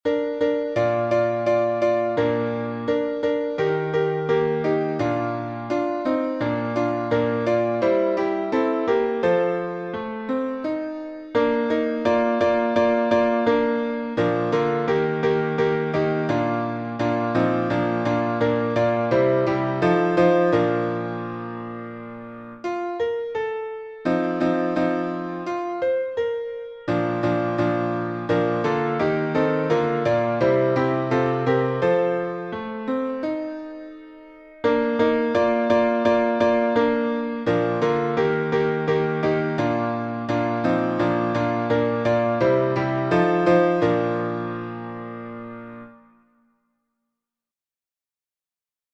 Williams (1896-1910)Tune: There’s a dear and precious Book by Charles Davis Tillman (1861-1943)Key signature: B flat major (2 flats)Time signature: 4/4Meter: 7.7.11.D. with RefrainPublic Domain1.